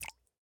drip_water1.ogg